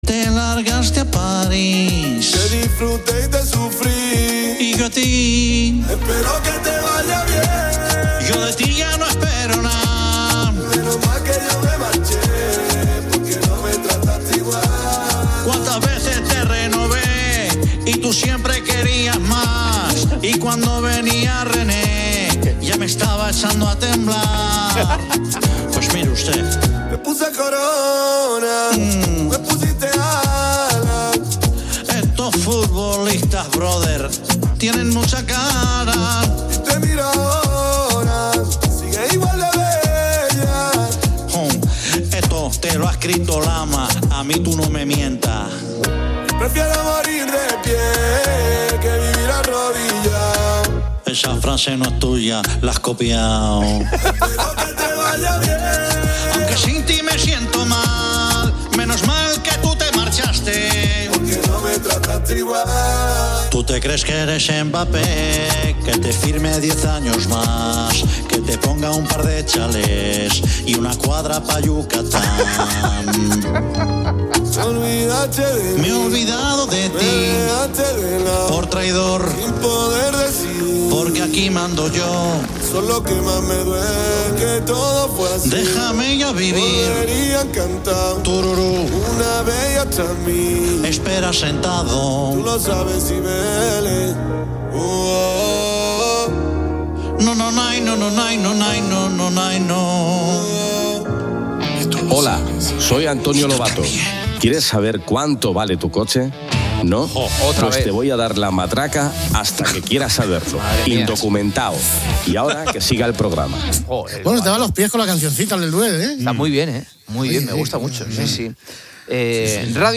El audio presenta una mezcla de música y segmentos de programas de radio. Destacan una llamada de broma a un supermercado donde un equipo ciclista (Astana) intenta comprar una gran cantidad de alimentos, alegando que les robaron su cena, pidiendo desde carne y alcohol hasta snacks. Luego, el programa 'Poniendo las calles' de la cadena Cope, con Carlos Moreno 'El Pulpo', aborda temas relacionados con el medio rural. Se entrevista a Gracia Canales, viceconsejera de Agricultura de Castilla-La Mancha, quien habla sobre la situación de los incendios forestales en la región y la importancia de la 'Escuela de Pastores'.